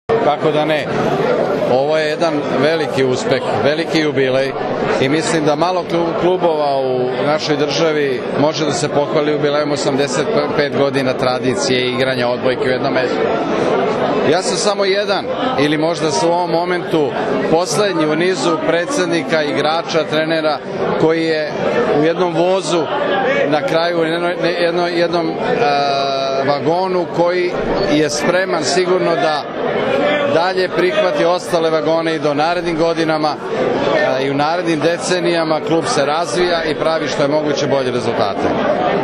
Odbojkaški klub “Jedinstvo” iz Stare Pazove proslavio je sinoć 85 godina postojanja na svečanosti održanoj u hotelu “Vojvodina” u Staroj Pazovi.
IZJAVA